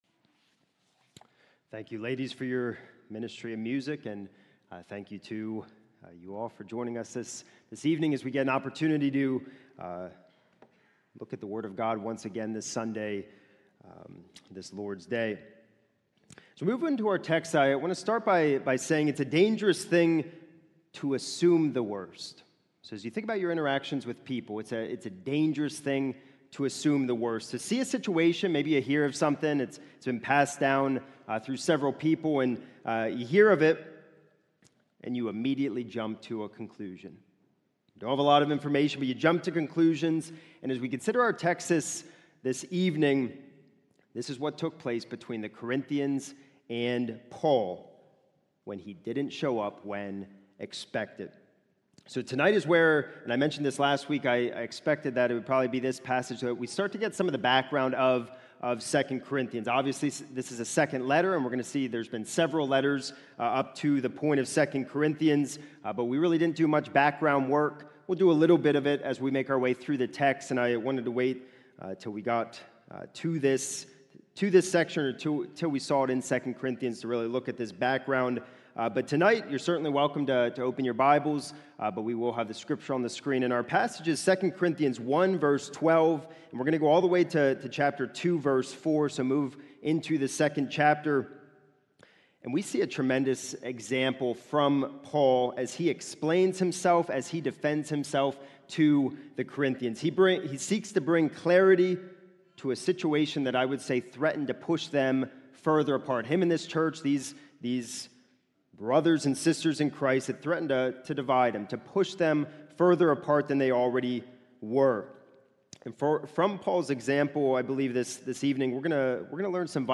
This is a sermon recorded at the Lebanon Bible Fellowship Church in Lebanon